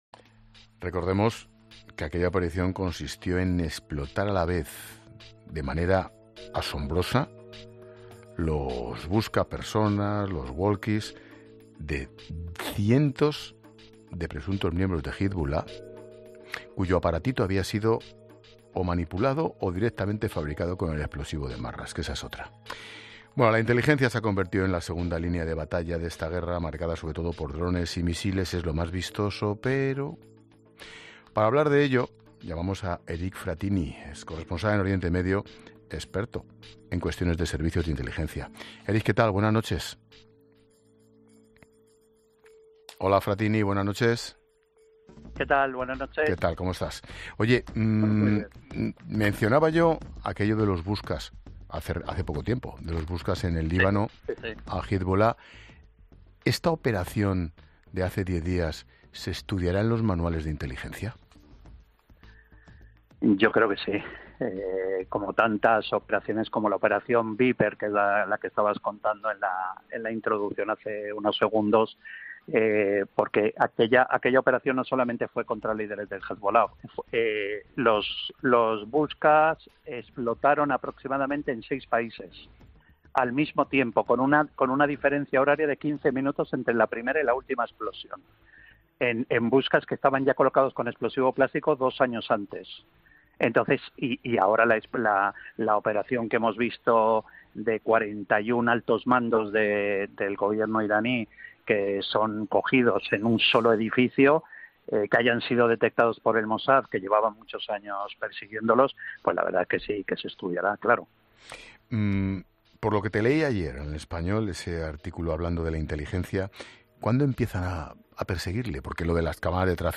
El periodista y experto en espionaje, Eric Frattini, desvela en 'La Linterna' las claves del poder de los servicios de inteligencia en el conflicto entre Israel e Irán